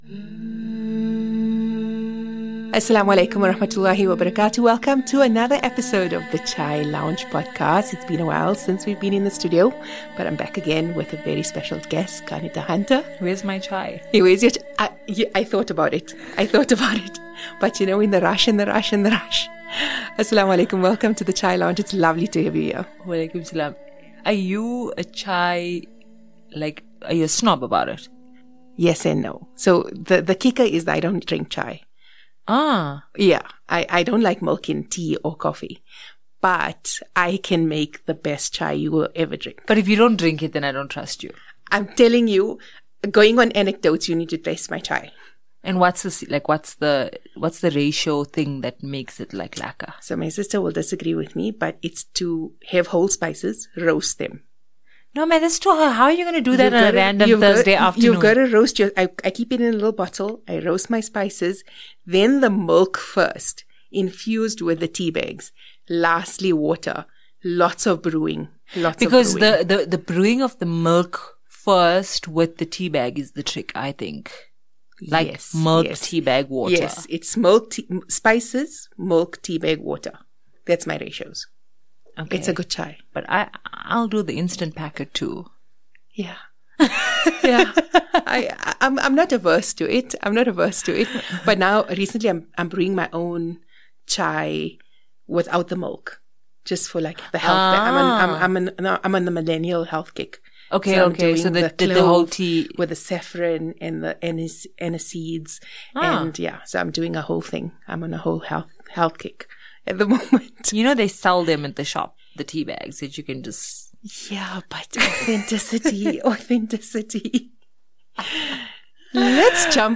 Bring your tissues and your tea - this conversation will make you laugh, reflect, and shed a tear. Stay tuned until the end for a fun quiz to help you connect with the book’s characters.